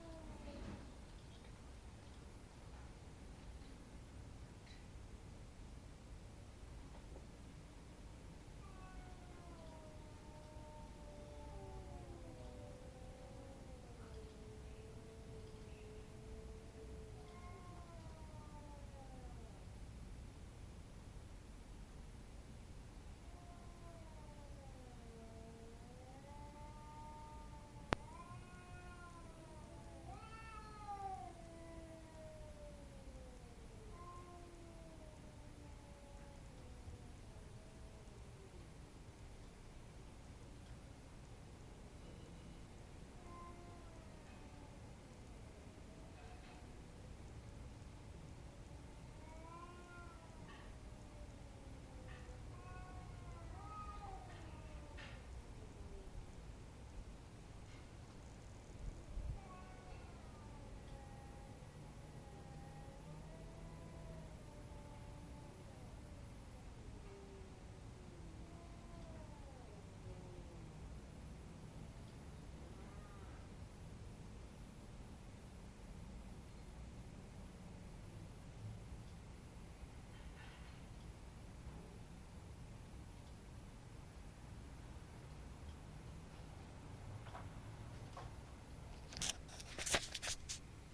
StandingNearMewingCats